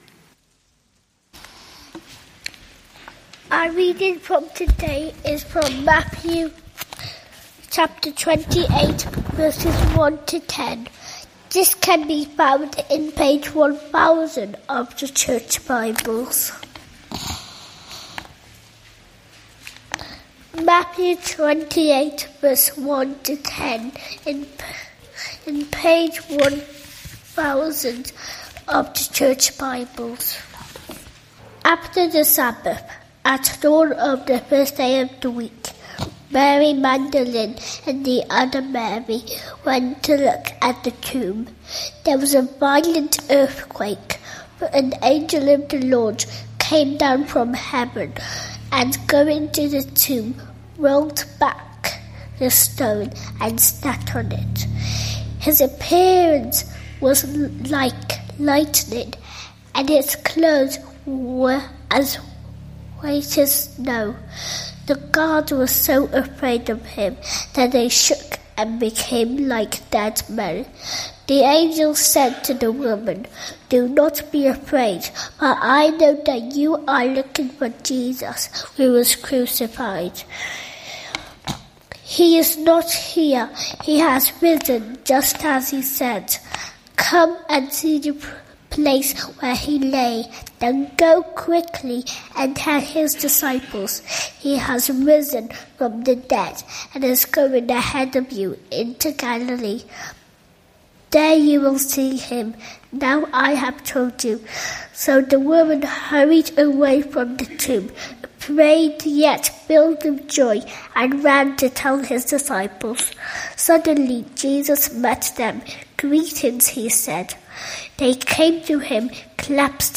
Matthew 28:1-10; 20 April 2025, Morning Service.